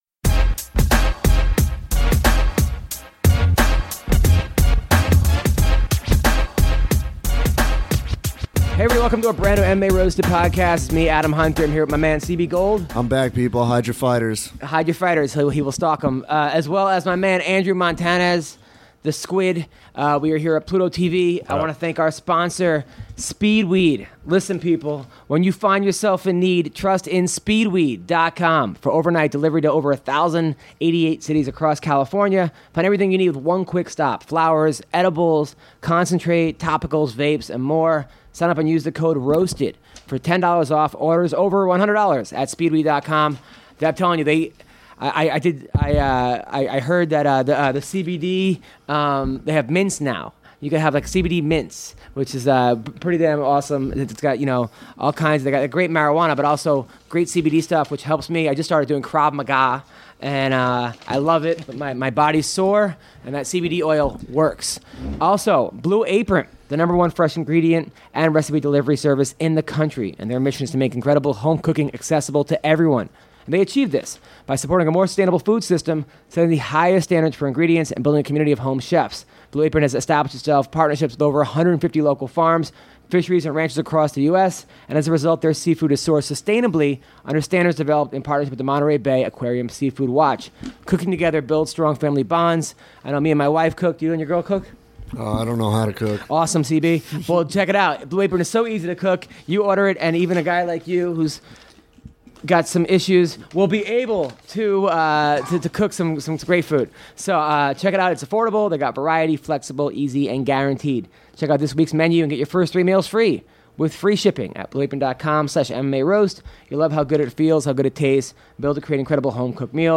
UFC fighter John Dodson calls in.